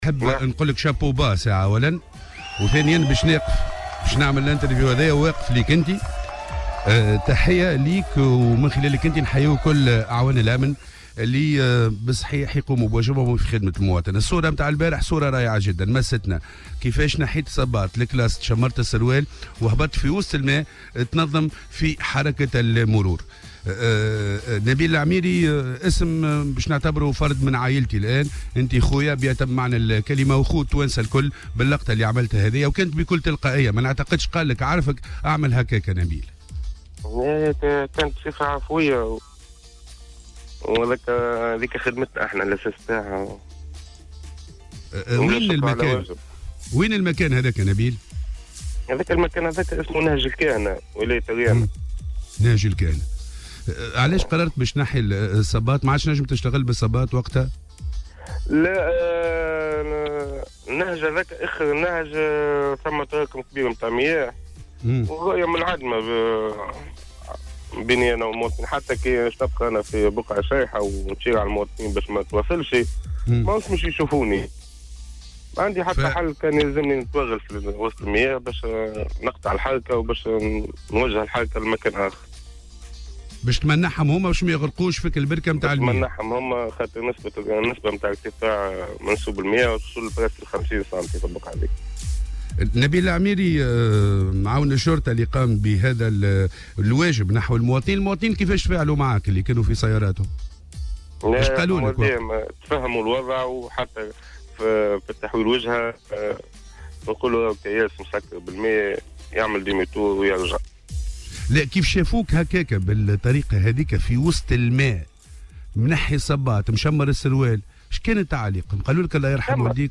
في مداخلة له اليوم في برنامج "صباح الورد"